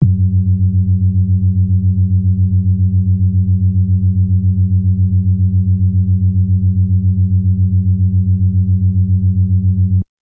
标签： F4 MIDI音符-66 赤-AX80 合成器 单票据 多重采样
声道立体声